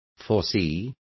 Complete with pronunciation of the translation of foreseeing.